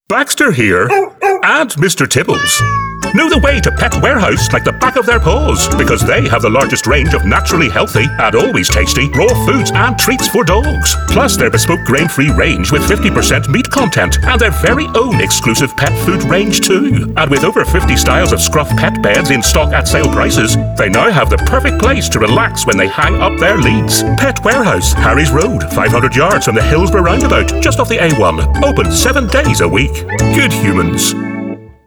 A Northern Irish Voice
Amusing Hardsell Radio com
I have a Northern Irish Accent which can be street or upperclass.